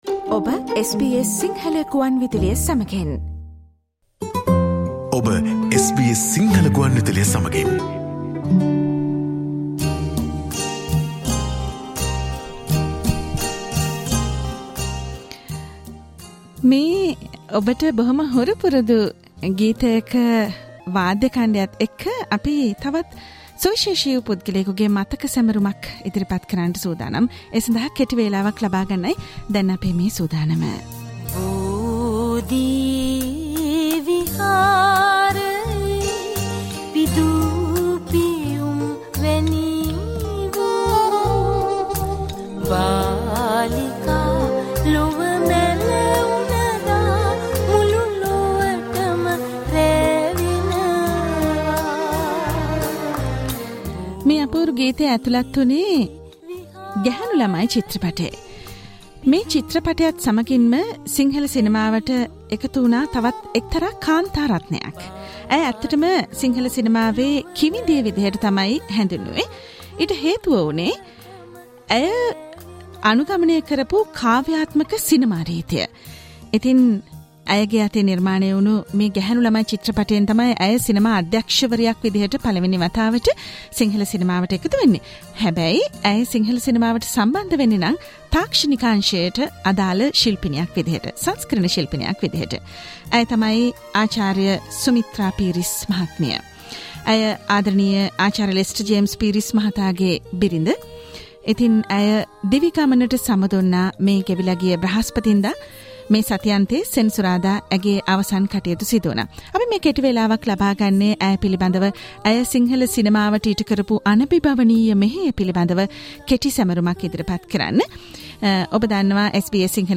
This is a short commemorative program that SBS Sinhala radio has broadcast to tribute to her enormous service to the Sinhala cinema.